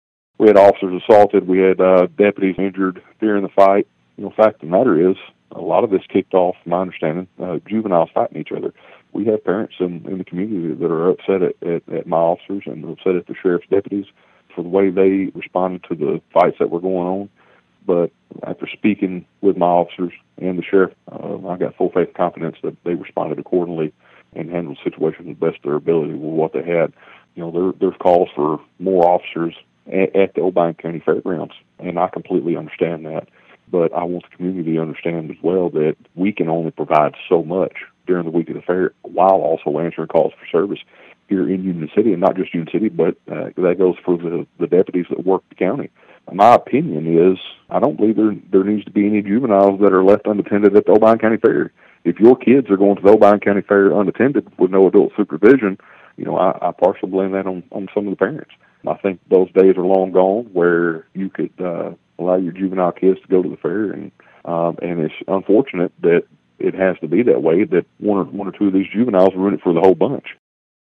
Chief Yates said his officers acted accordingly in trying to help de-escalate the situation.(AUDIO)